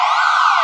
1 channel
M11SIREN.mp3